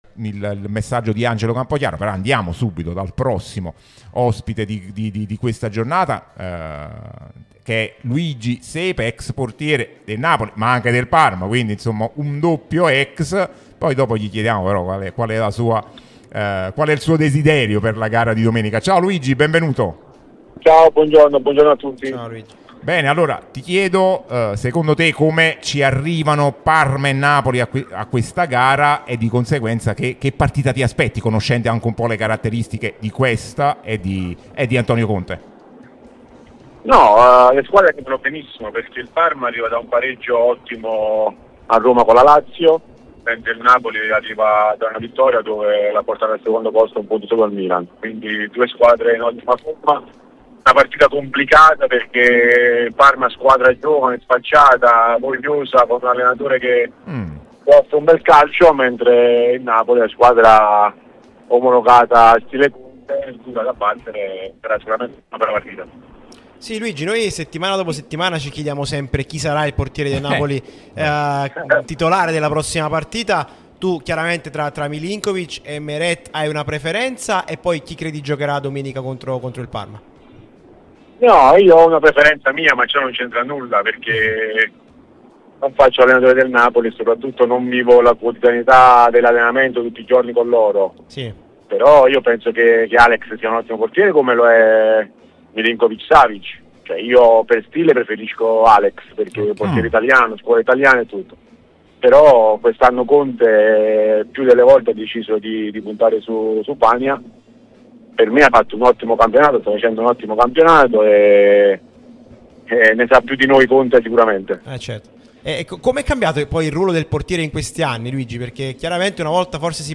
Luigi Sepe, ex portiere di Napoli e Parma, è intervenuto sulla nostra Radio Tutto Napoli, prima radio tematica sul Napoli